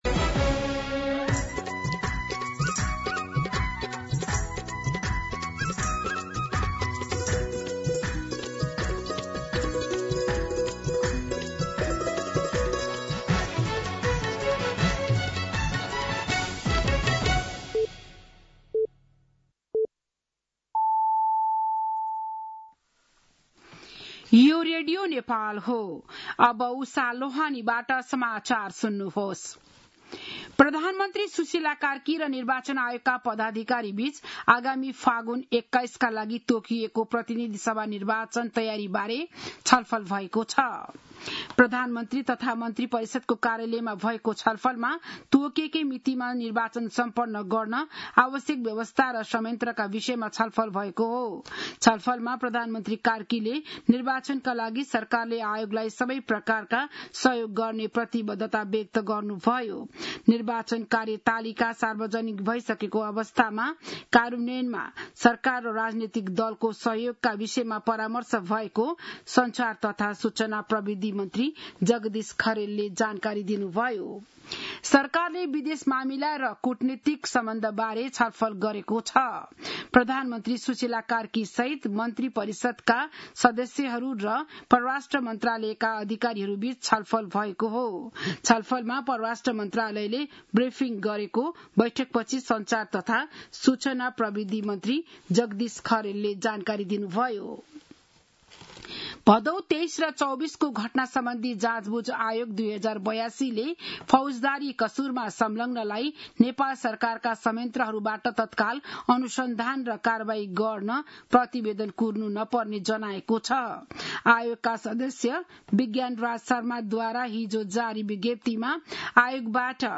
An online outlet of Nepal's national radio broadcaster
बिहान ११ बजेको नेपाली समाचार : २५ साउन , २०८२